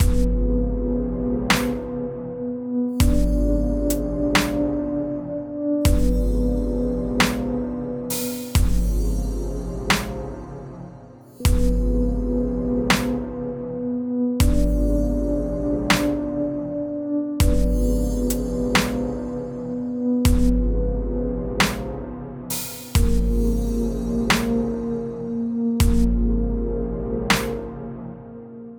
Horror , Suspenseful